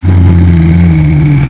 boil_idle1.wav